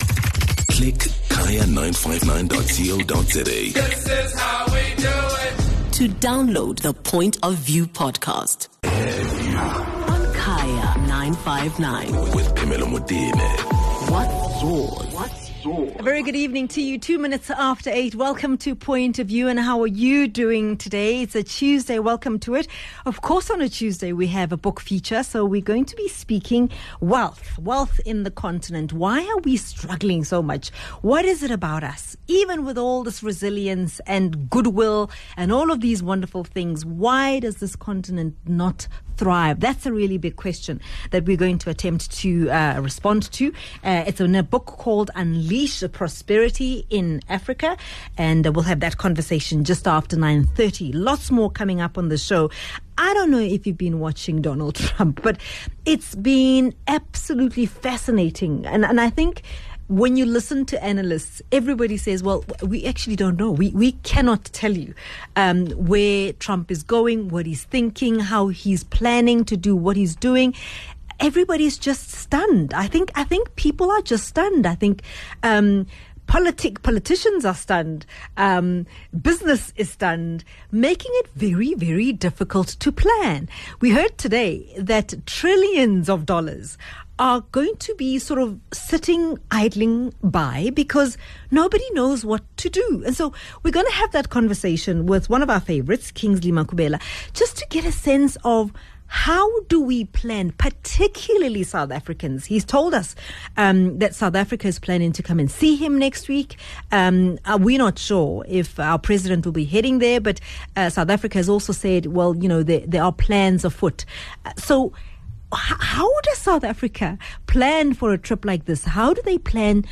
speaks to legal expert